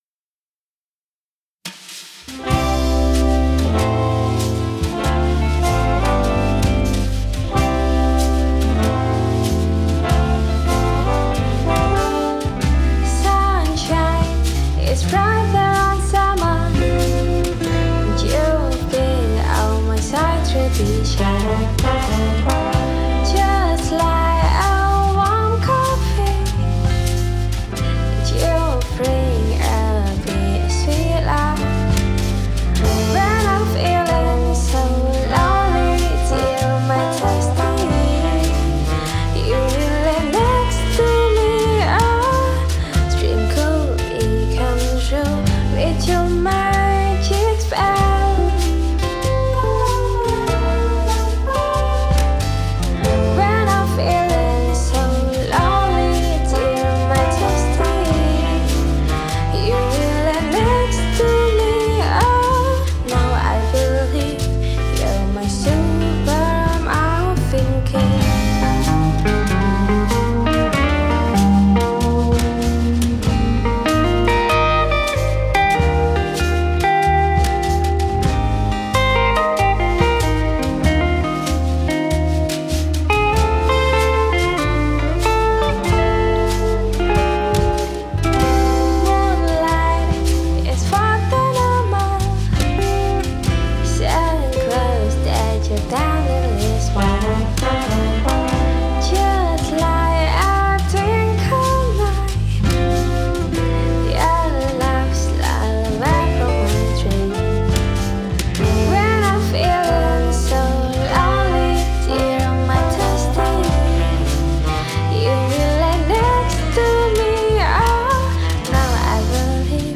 Magelang Pop
Pop-Jazz